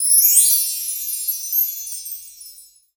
percussion 17.wav